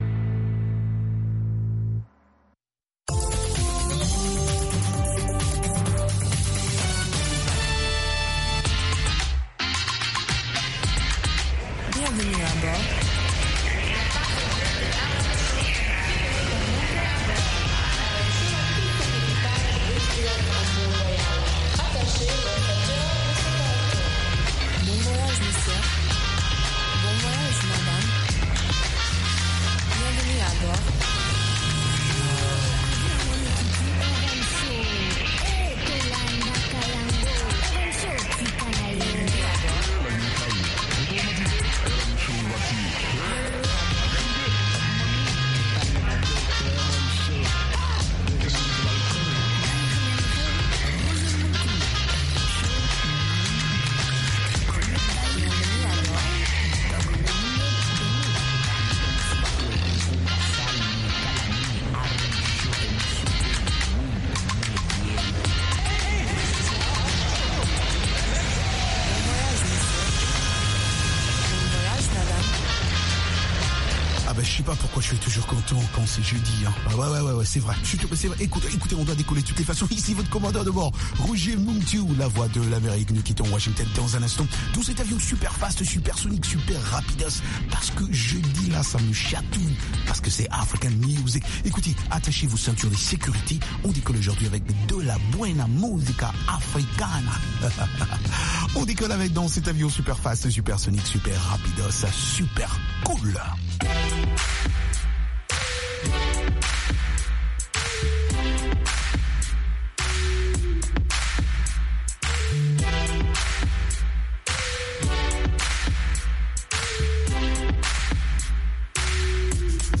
des reportages et interviews sur des événements et spectacles africains aux USA ou en Afrique.